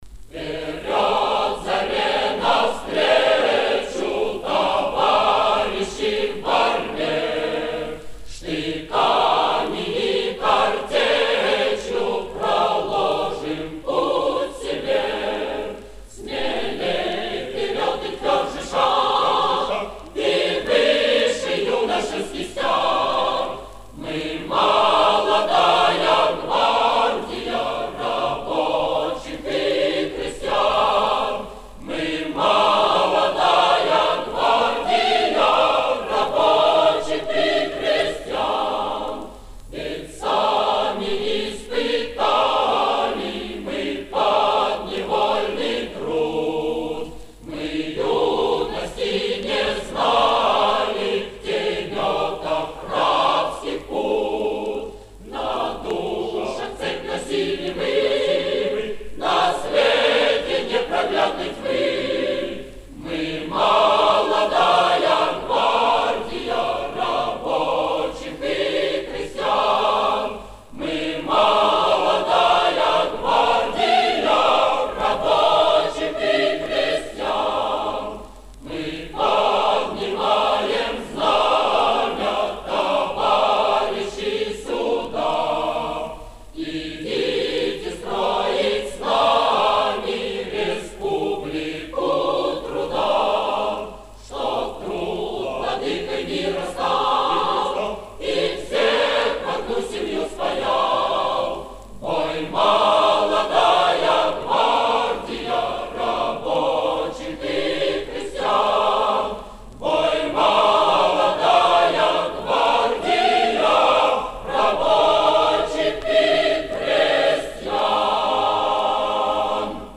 Вариант исполнение а капелла.